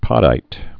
(pŏdīt)